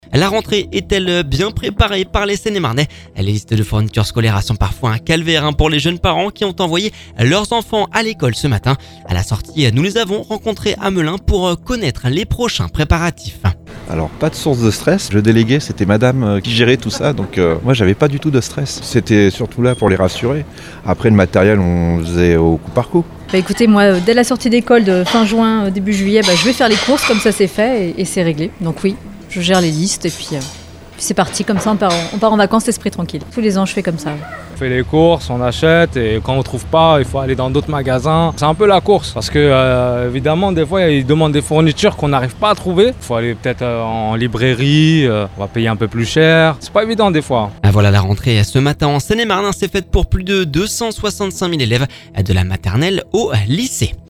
A la sortie de l’école nous les avons rencontrés à Melun pour connaître les prochains préparatifs…